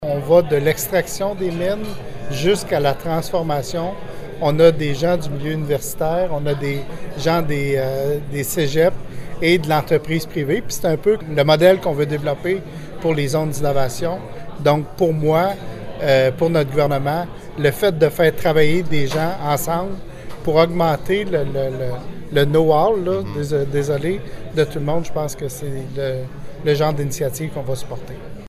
Présent au lancement, le député de Nicolet-Bécancour, Donald Martel, a exprimé sa foi dans ce genre de synergie entre les entreprises et les institutions d’enseignement.